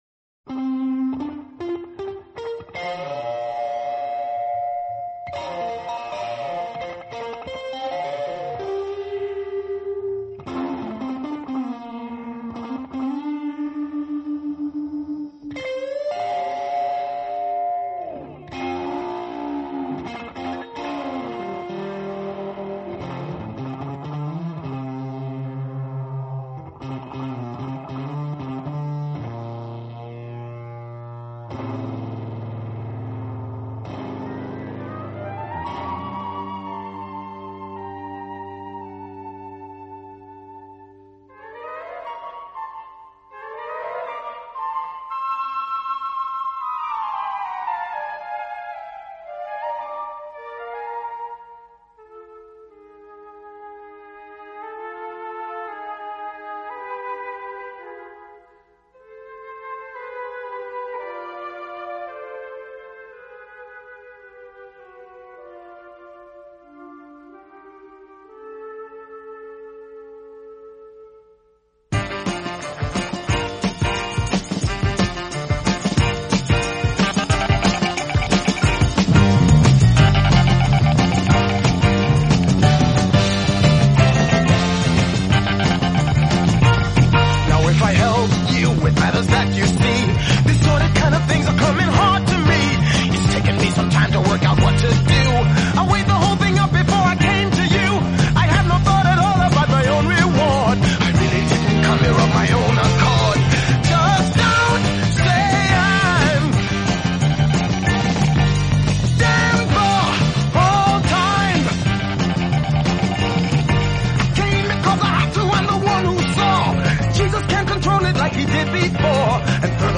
Dist. elec. guitar & flute opening